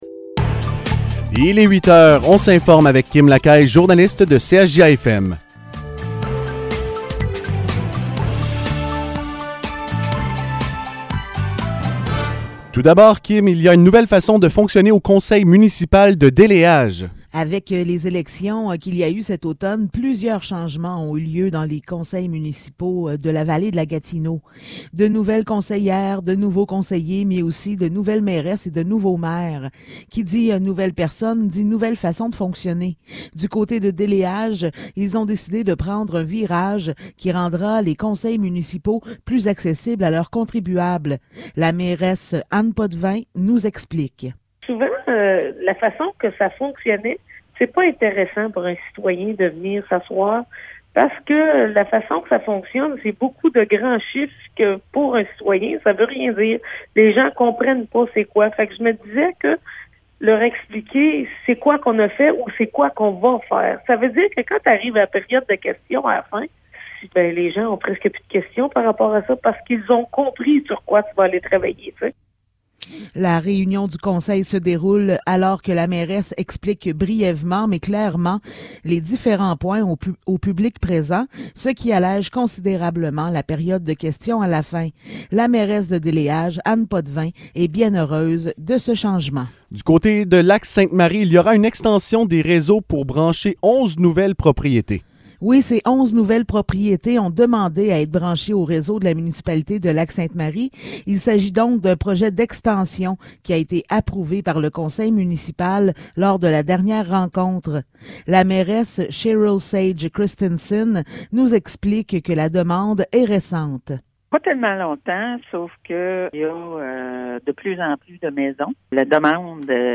Nouvelles locales - 31 décembre 2021 - 8 h